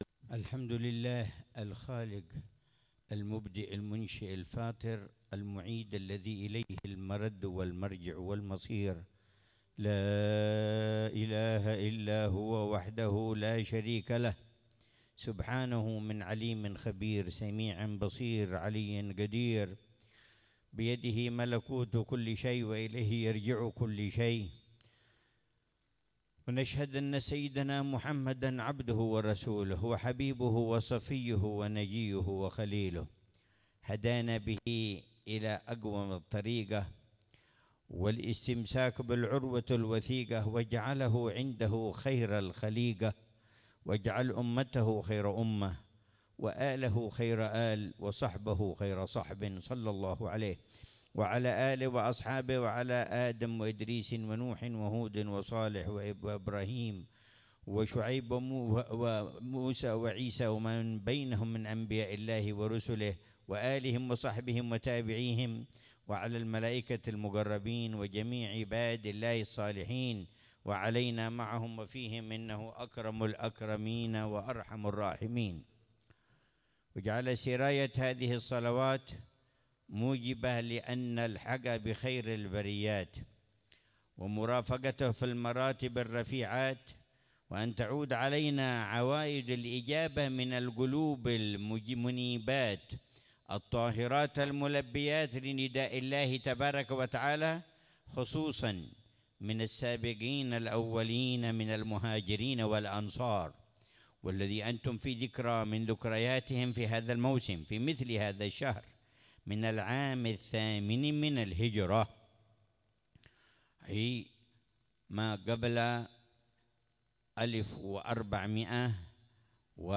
محاضرة العلامة الحبيب عمر بن محمد بن حفيظ في مجلس البردة الأسبوعي في مسجد الروضة المباركة في عمان، الأردن، ليلة الجمعة 13 جمادى الأولى 1446هـ بعنوان: